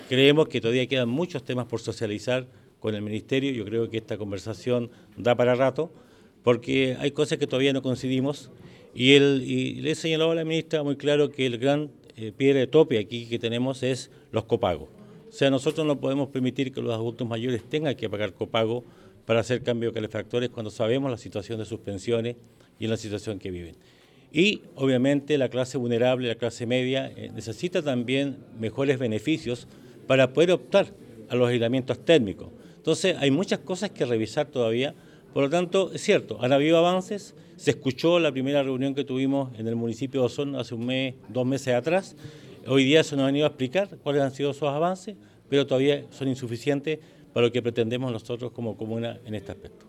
El alcalde de Osorno Emeterio Carrillo, señaló que aún se mantienen ciertas discrepancias con el Plan de Descontaminación, pues a su parecer el anterior PDAO no dio resultado en la comuna.
El jefe comunal agregó que existen aspectos que deben abordarse de manera concreta como el copago para el recambio de calefactores y las medidas de aislamiento térmico.